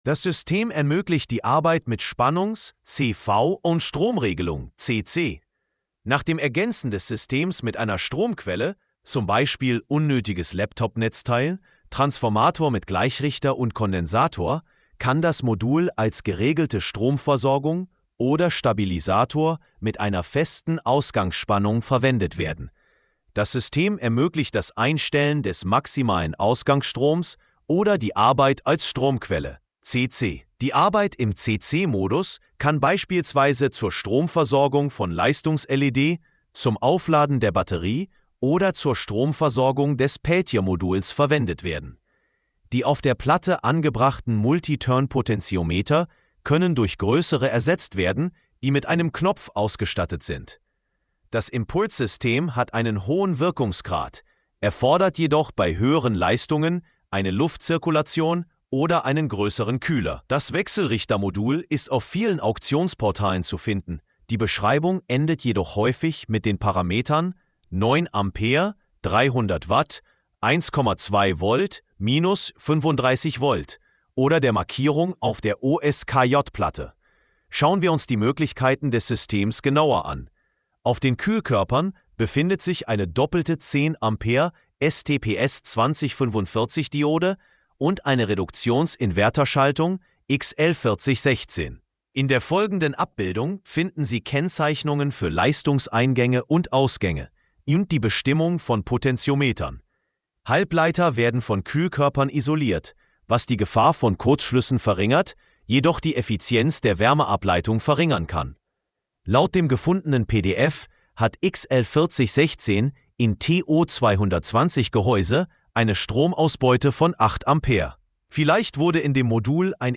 📢 Anhören (AI):